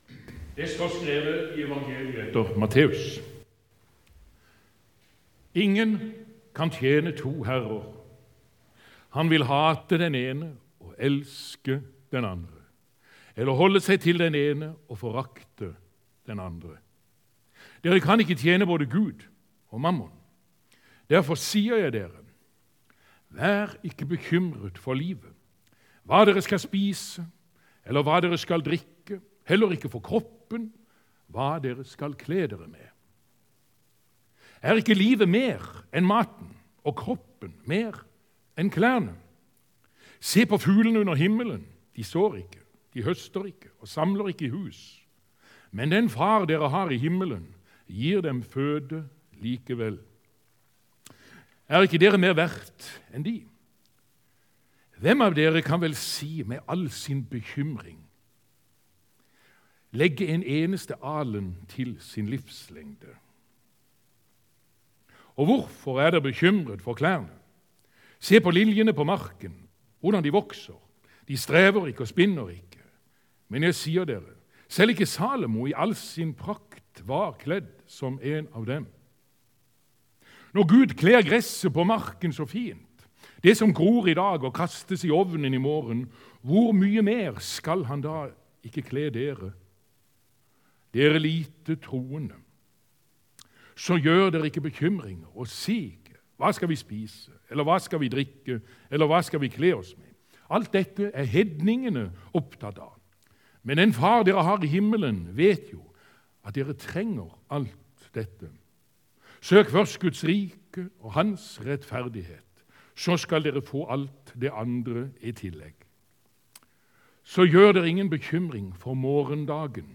Her finner du lydopptak av taler ved gudstjenester i Tveit menighet.